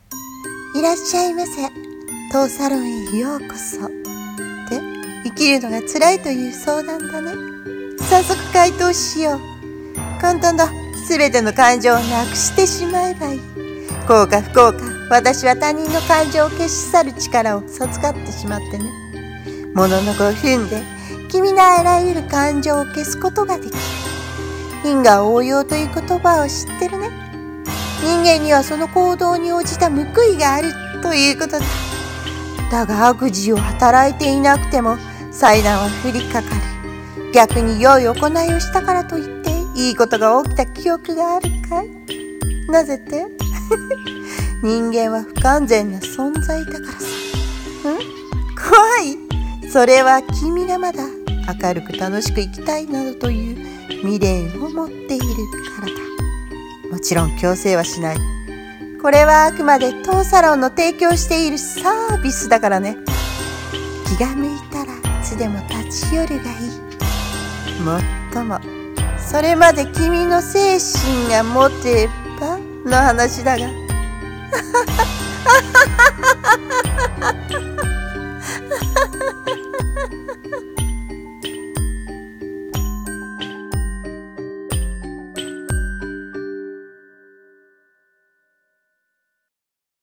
1人声劇台本「感情消滅サロン